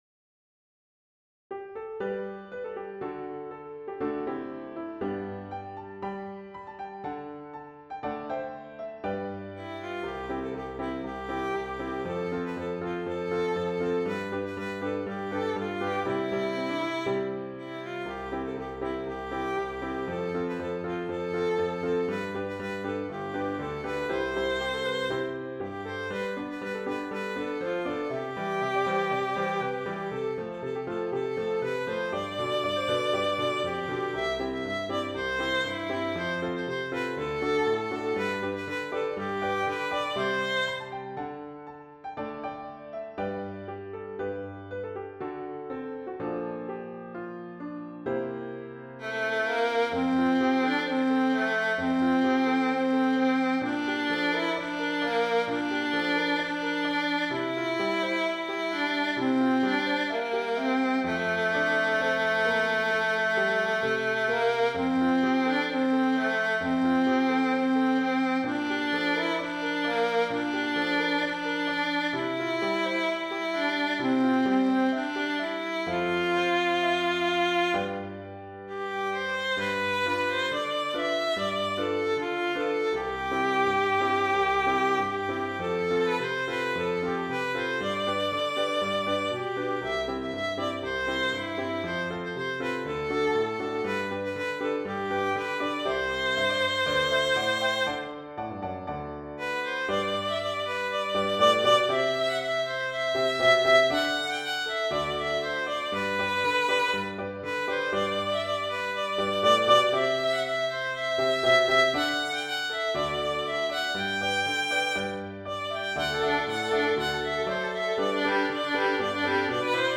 Intermediate Instrumental Solo with Piano Accompaniment.
Christian, Gospel, Sacred.
fast past, energetic jig.